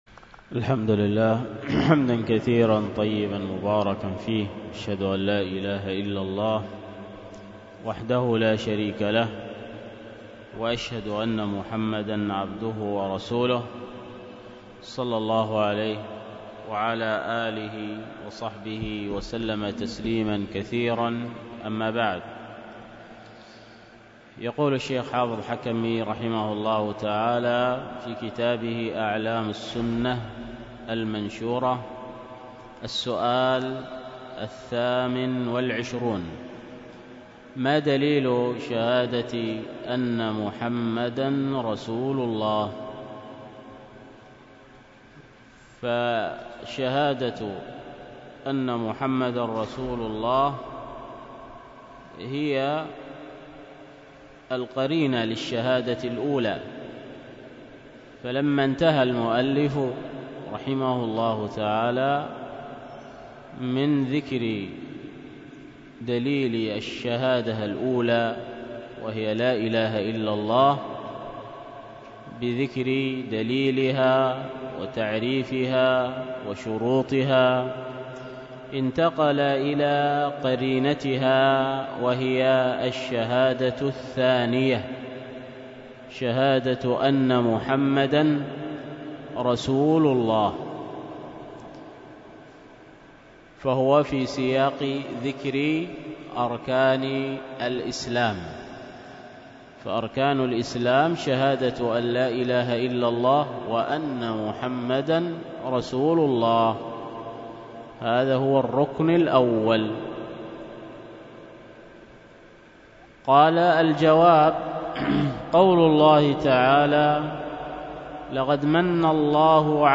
تحميل الدرس